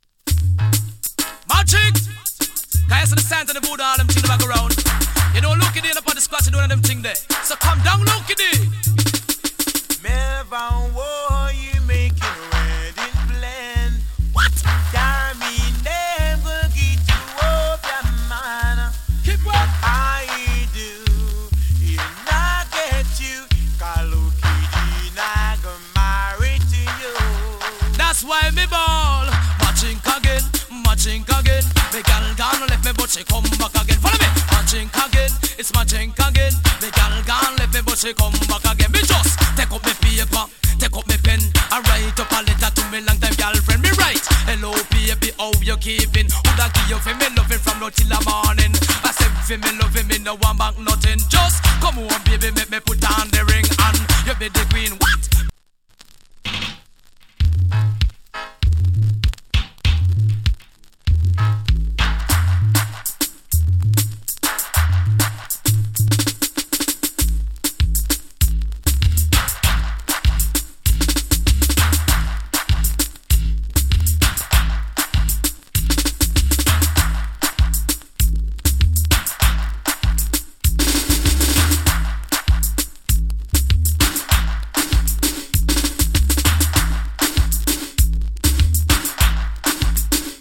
DANCE HALL 90'S
A：EX- / B：EX- ＊ジリノイズ少し有り。チリ、パチノイズわずかに有り。